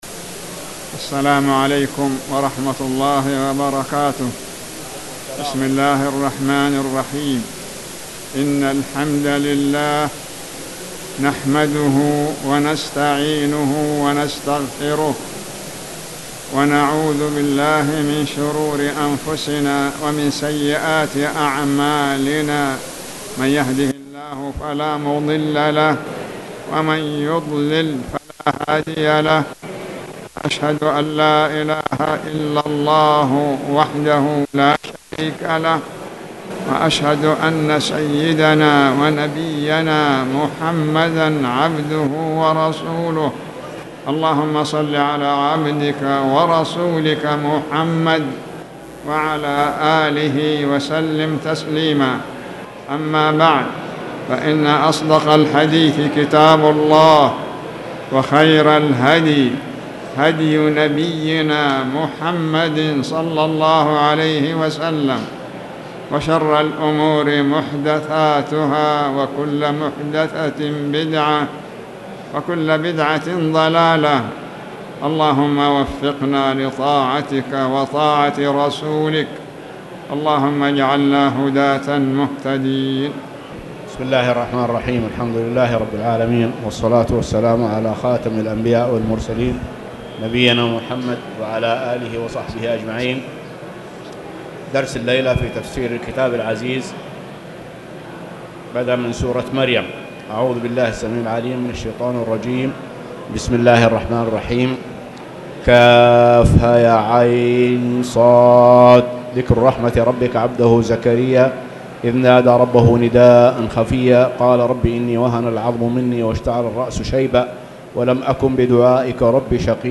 تاريخ النشر ٨ ربيع الأول ١٤٣٨ هـ المكان: المسجد الحرام الشيخ